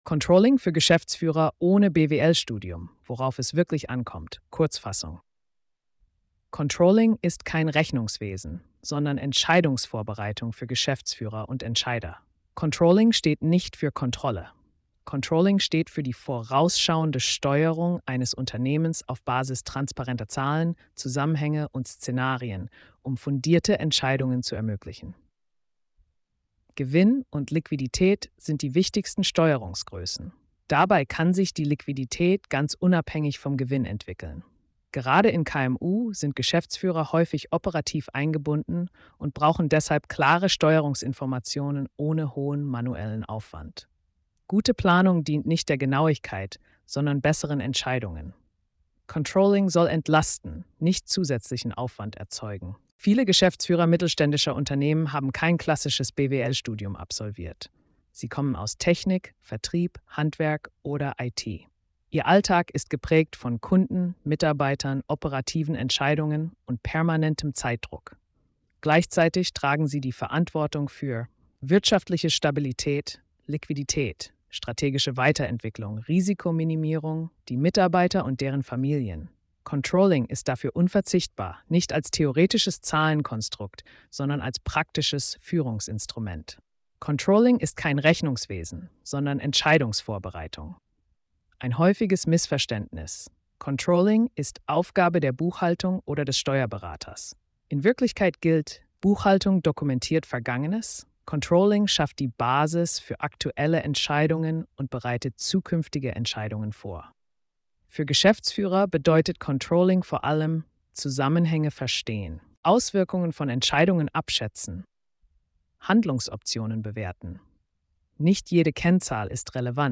Symbolbild Sprachausgabe